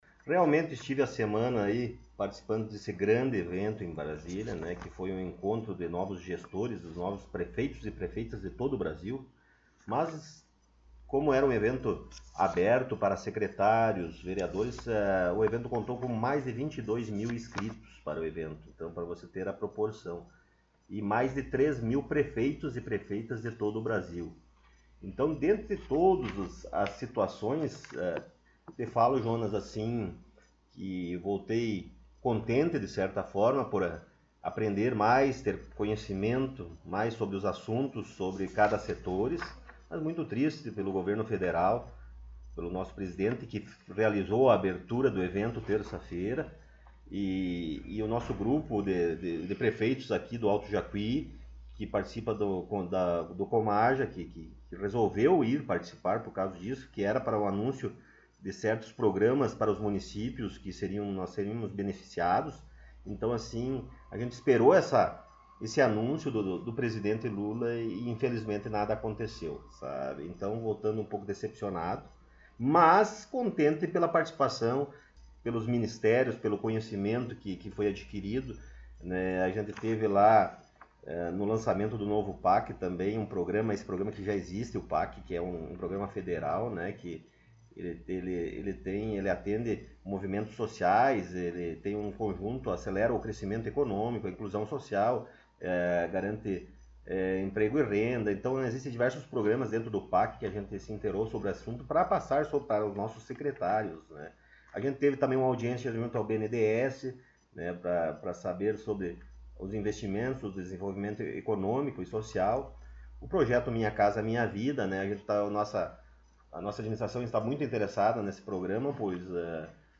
Após ida à Brasília o prefeito Rodrigo Sartori concedeu entrevista
Com a intuição de sabermos informações sobre a sua ida à Brasília e demais informações pertinentes a Administração Municipal, nós conversamos com ele na última sexta-feira no Gabinete da Prefeitura Municipal.